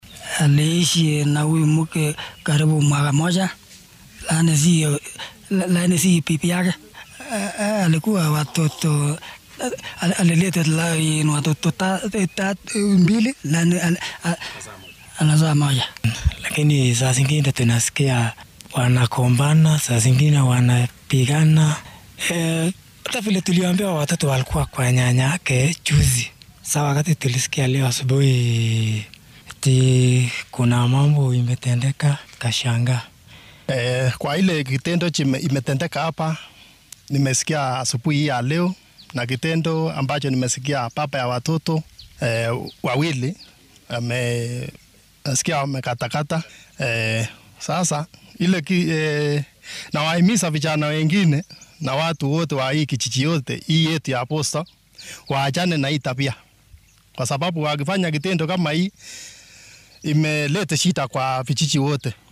Dadka deegaanka ayaa dhacdadaan ka hadlay.